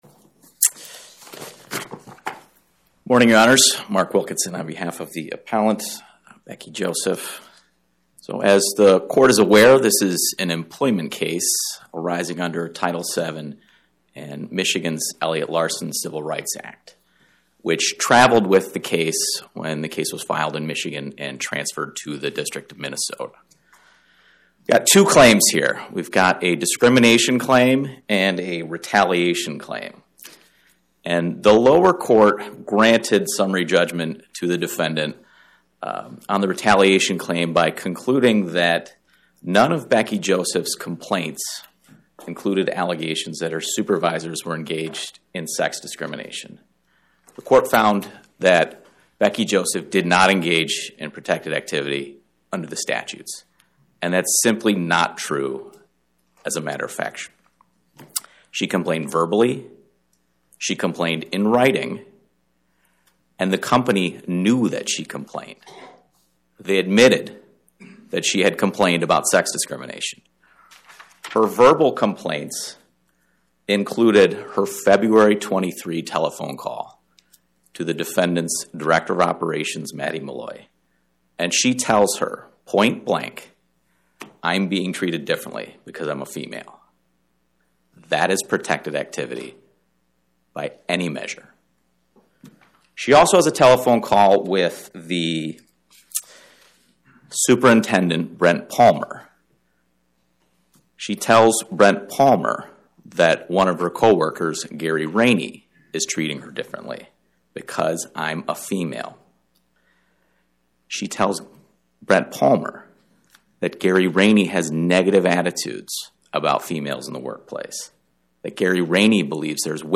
Inc. Podcast: Oral Arguments from the Eighth Circuit U.S. Court of Appeals Published On: Tue Mar 17 2026 Description: Oral argument argued before the Eighth Circuit U.S. Court of Appeals on or about 03/17/2026